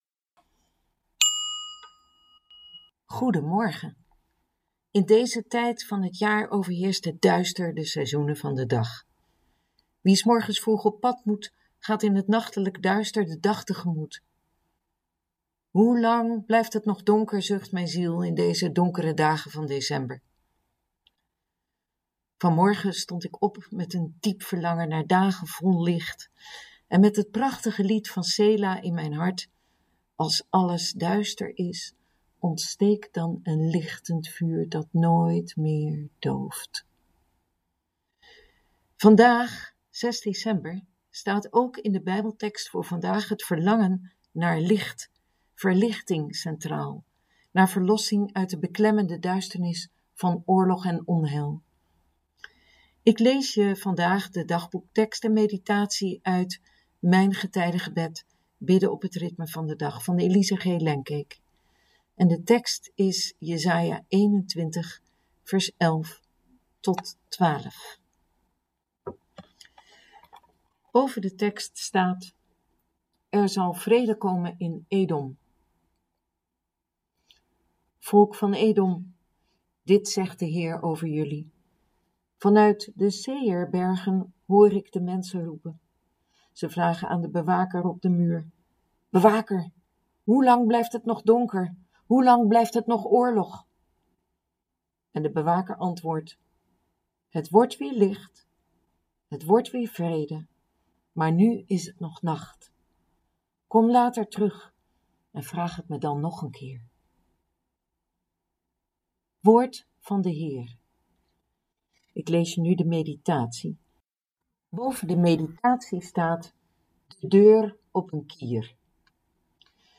Ik lees je vandaag de dagboektekst en meditatie uit Mijn getijdengebed